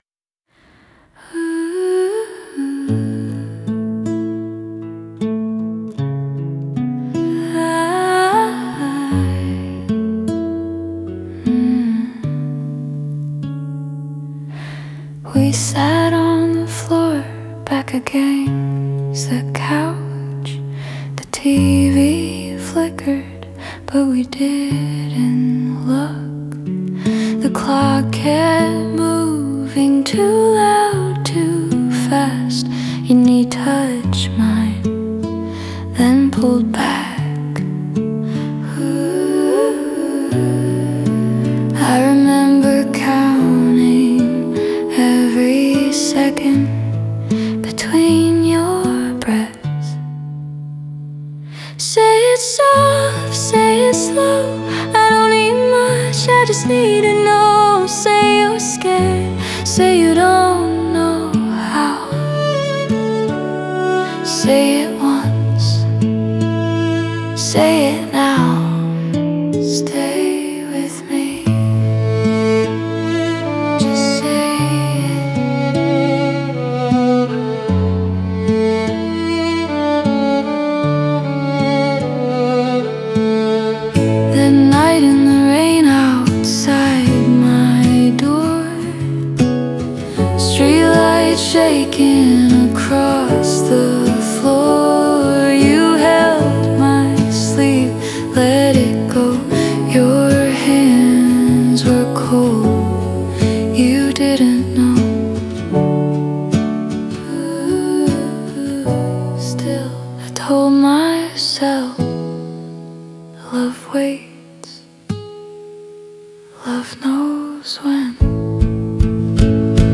オリジナル曲♪
曲は静かに始まり、コーラスとハーモニーを重ねながら感情が解放され、最後に頂点へ到達する流れとなっている。